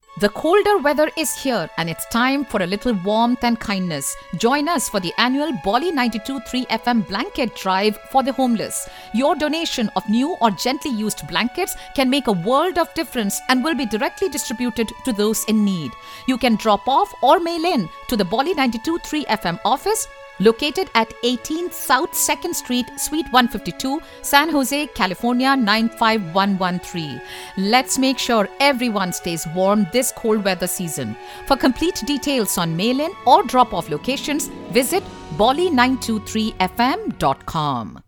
A short community service announcement from Bolly 92.3 FM (KSJO San Jose) promoting the station’s Blanket Drive for those in need during the winter season. The clip highlights the station’s role in engaging listeners through charitable efforts and civic awareness, showcasing how South Asian media in the Bay Area extends beyond entertainment to serve the broader community.